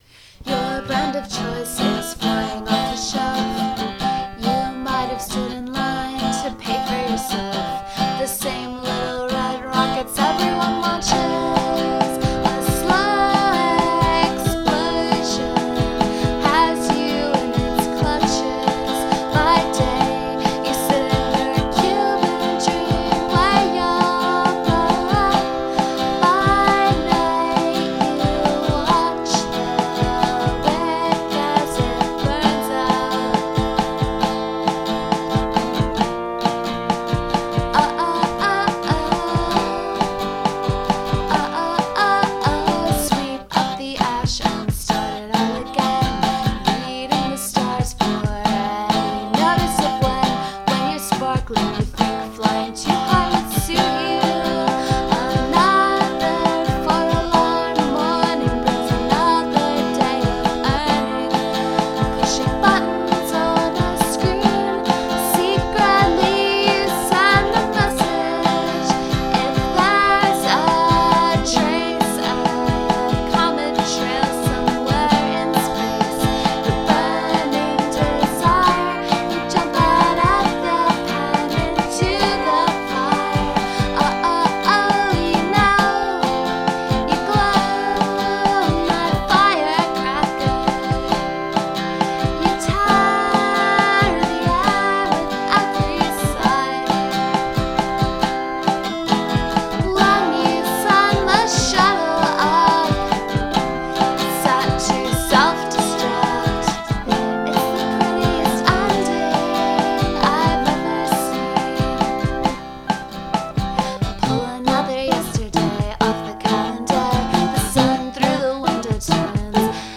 old version with a lot of words